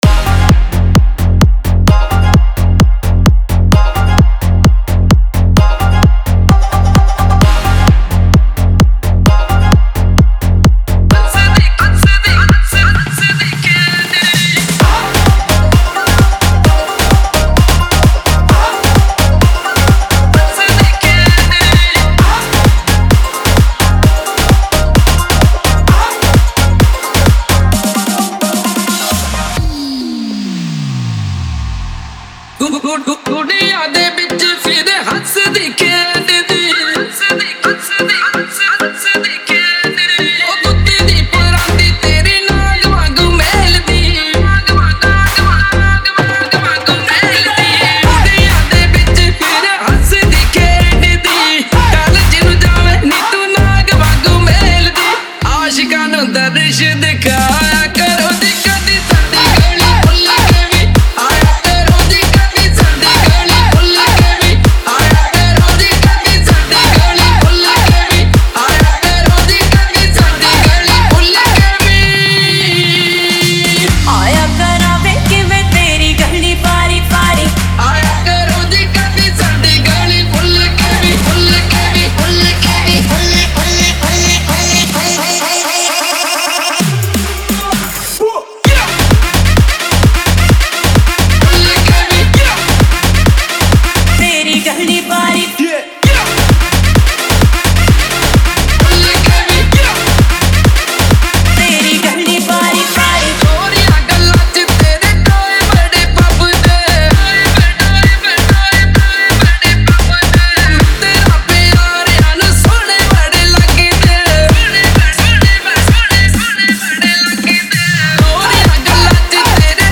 Bollywood Single Remixes